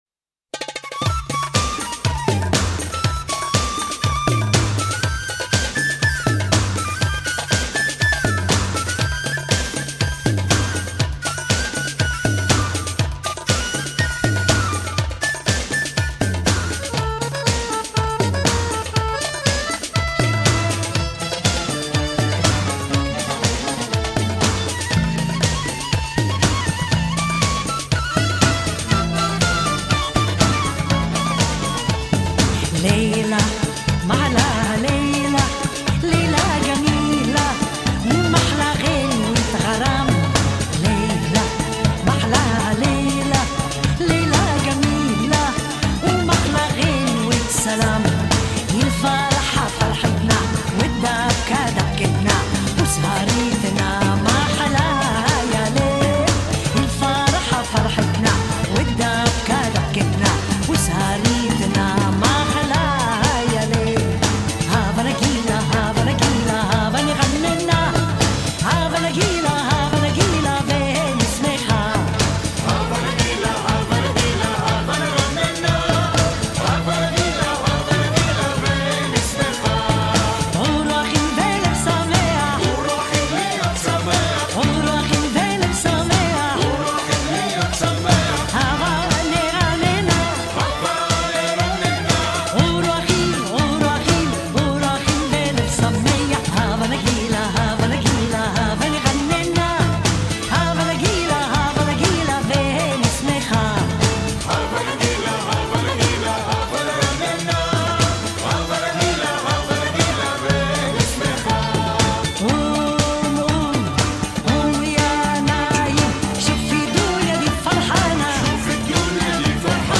Записано в феврале 1994 г.